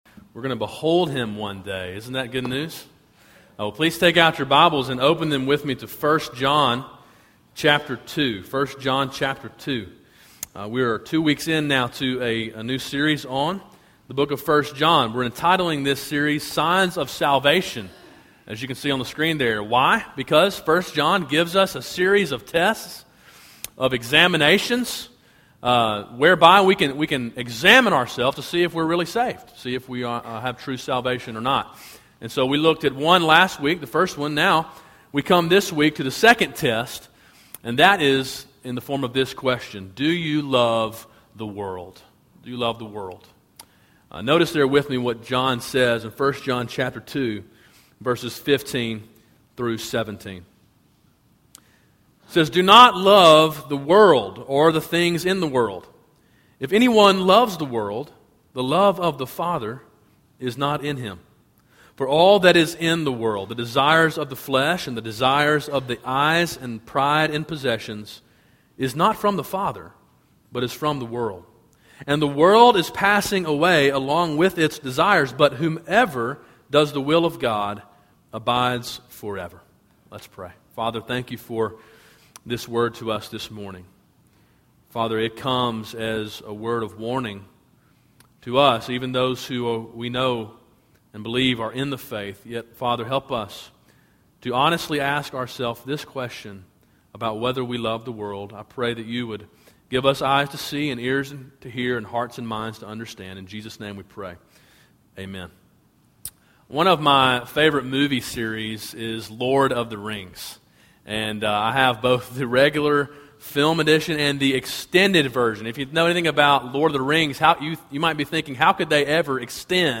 A sermon in a series on 1 John titled Signs of Salvation.